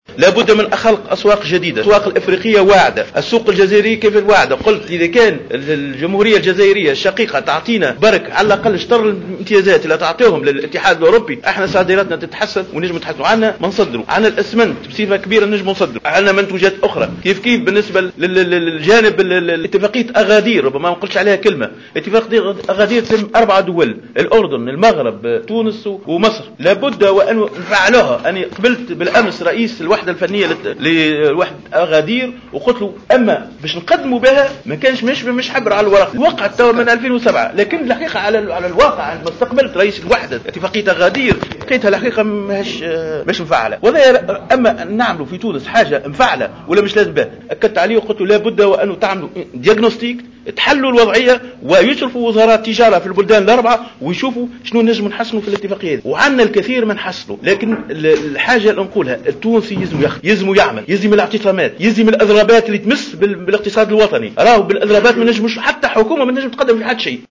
أكد وزير التجارة رضا لأحول على هامش حضوره في اجتماع لحركة نداء تونس لإحياء ذكرى مؤتمـر قصـر هـلال الثاني 2 مارس 1934 ضرورة خلق أسواق جديدة لتحسين الصادرات التونسية .